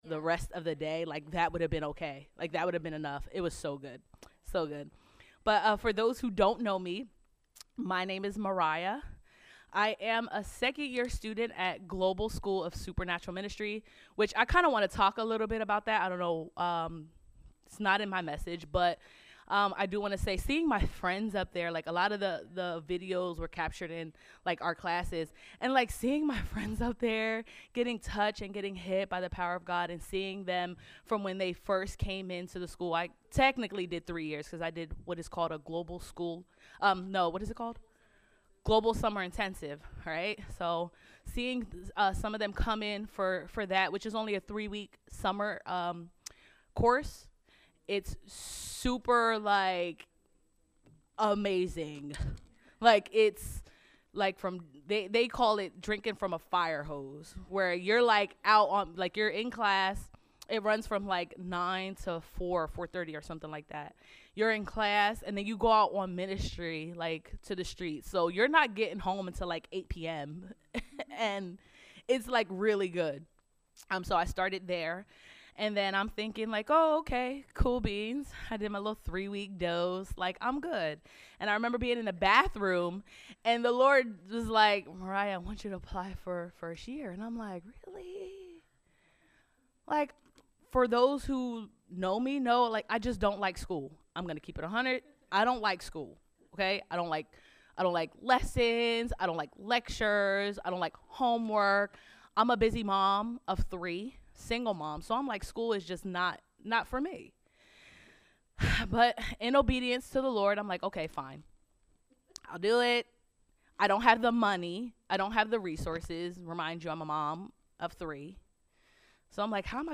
GSSM Empowerment Weekend Sunday Service